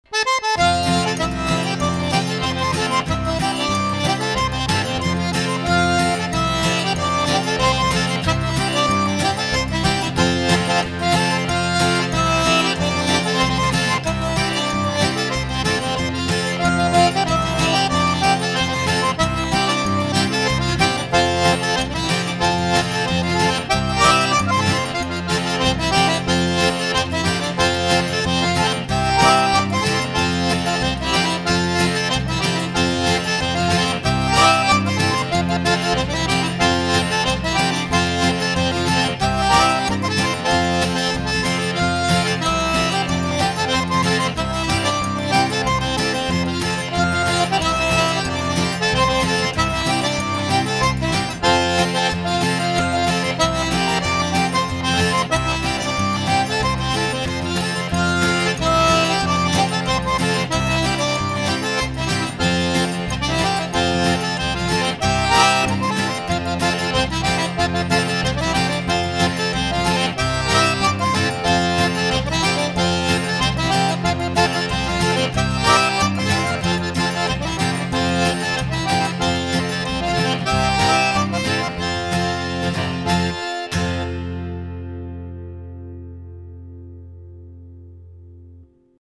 2005 is going to be another great year to travel to SW Louisiana and learn more about playing the Cajun accordion, dancing and eating.
So, what I've done here is recorded several clips of myself playing some of the tunes that I have been working on in hopes that others may be inspired to learn these tunes (or, accompaniments/fiddle parts to the tunes) that will serve as "jam fodder"!
These clips were paired down to a 9600 bit rate as MP3's so they stayed small.